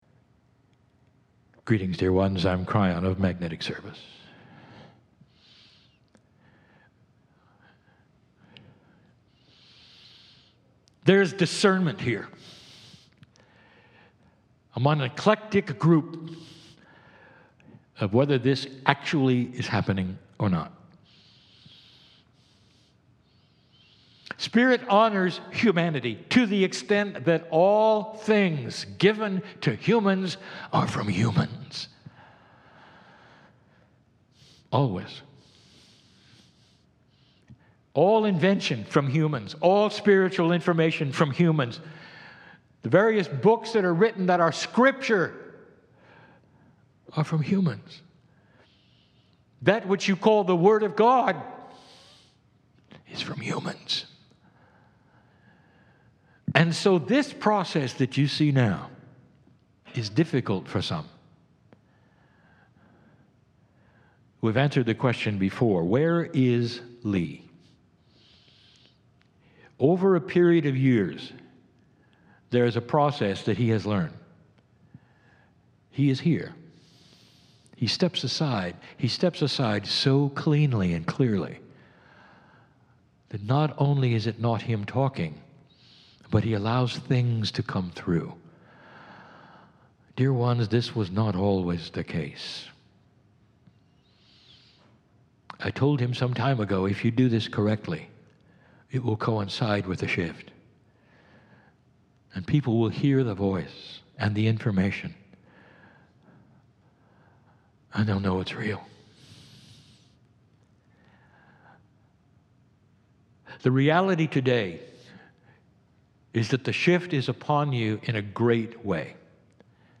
Live Kryon Channelling